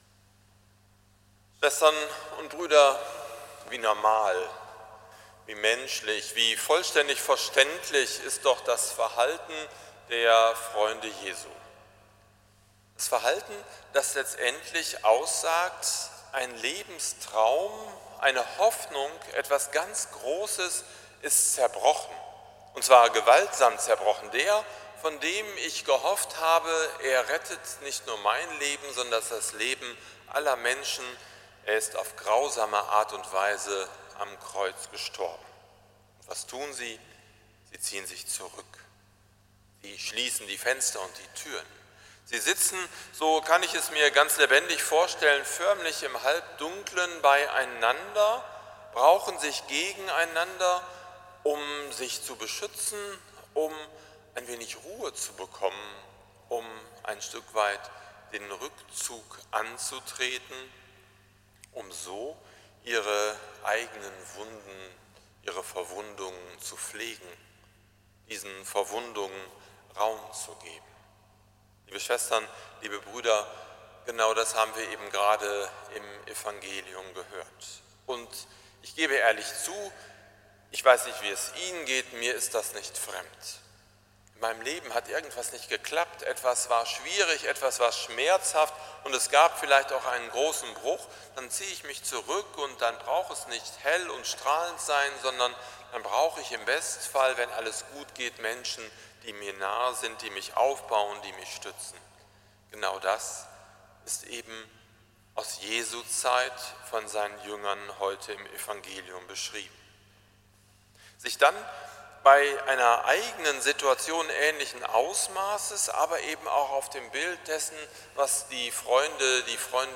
Predigt zum 2. Sonntag der Osterzeit 2019 – St. Nikolaus Münster
predigt-zum-2-sonntag-der-osterzeit-2019